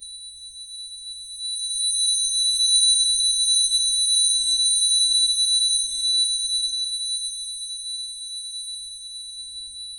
Soft pink sparkle chime, gentle bell tones with glittery shimmer, cute and feminine, sweet follower alert, 2 seconds
soft-pink-sparkle-chime-g-ujh7jn3t.wav